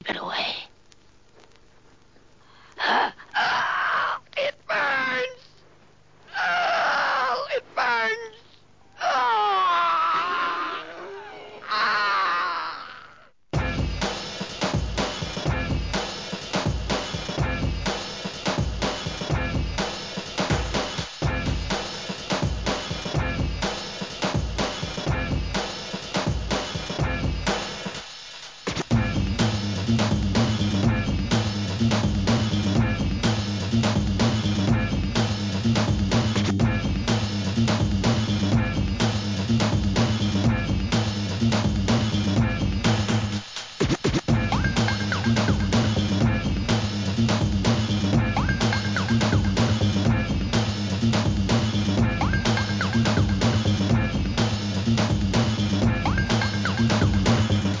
HIP HOP/R&B
ブレイカーへ向けたBREAK BEATS集第5弾!!